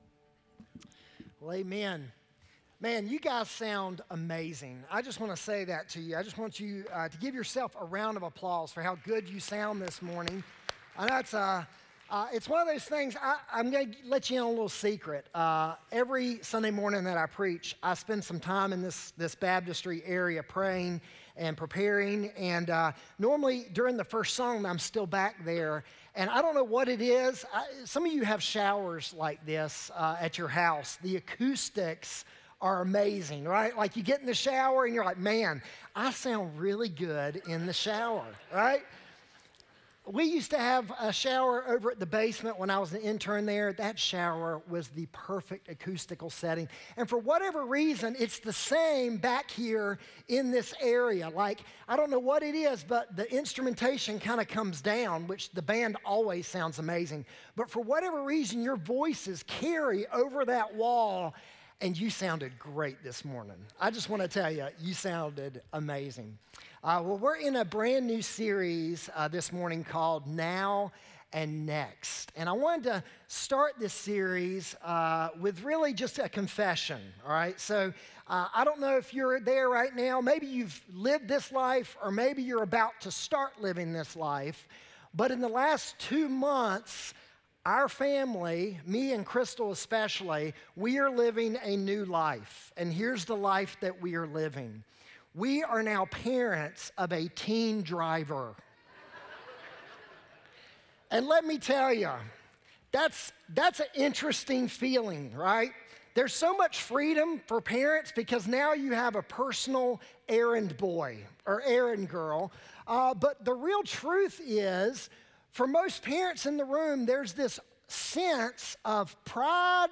2-23-25-sermon-audio.m4a